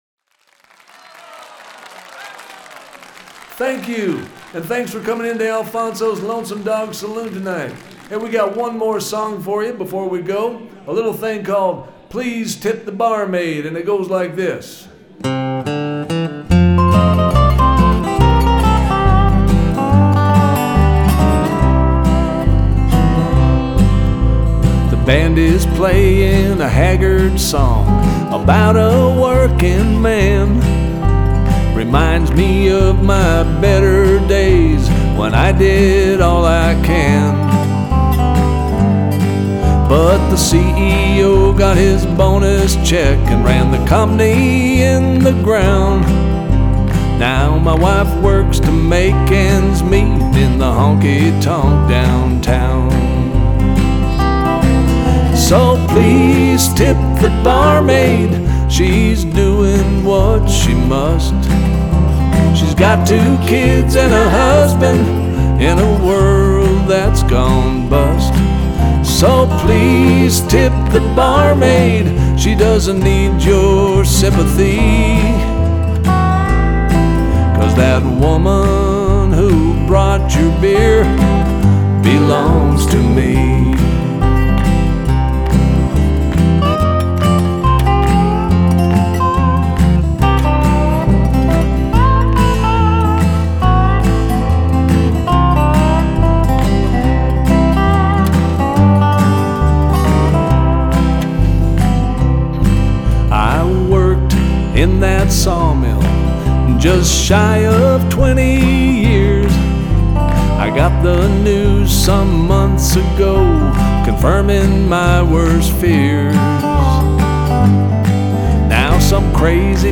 vocals, acoustic guitar
Dobro